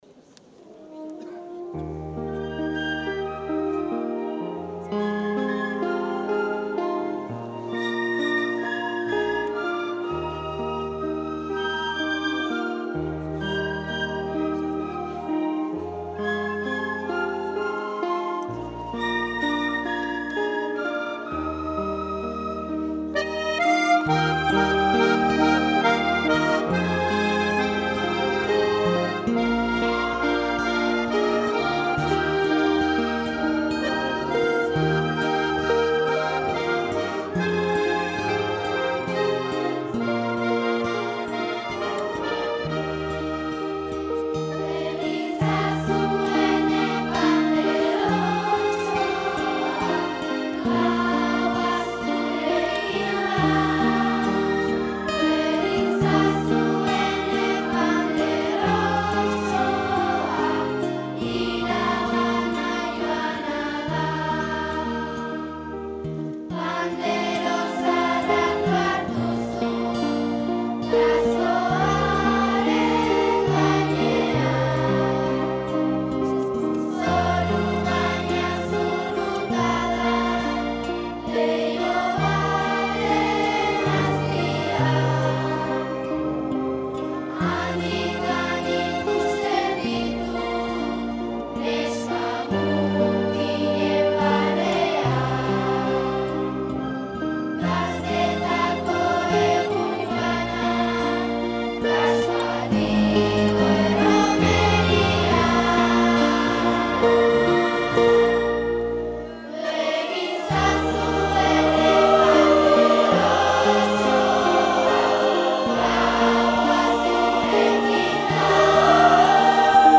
Mota: Txistu Alardeetarako Moldaketa
Txistu alardeetarako moldatua
Audio fitxategi mota: Midi fitxategia